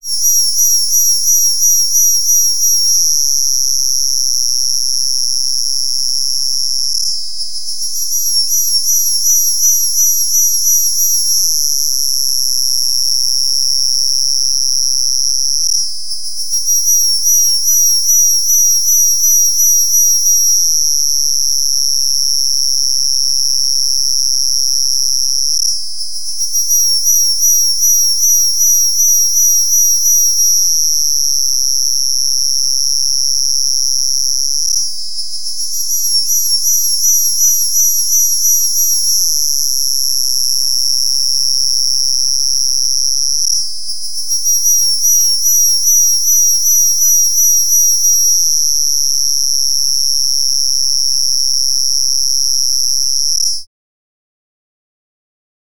Species: Minipomponia littldollae